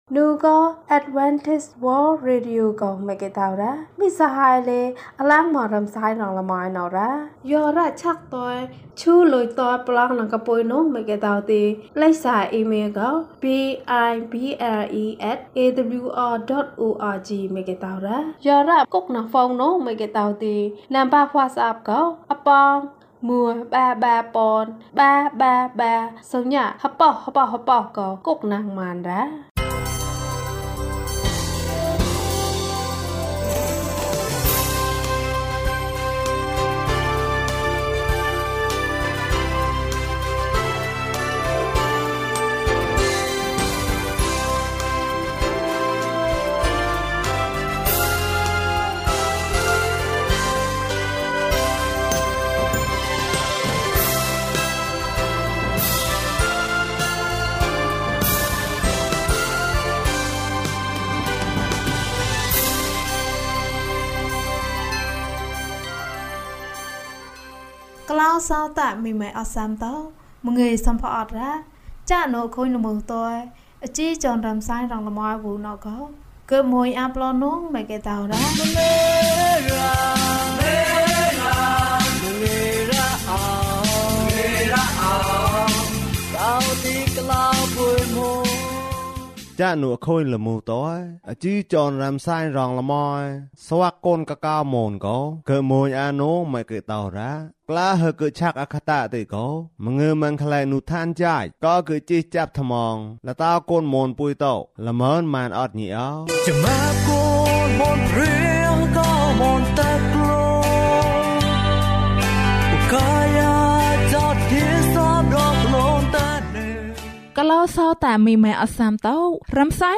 ဘုရားသခင်က ကောင်းမြတ်တယ်။ ကျန်းမာခြင်းအကြောင်းအရာ။ ဓမ္မသီချင်း။ တရားဒေသနာ။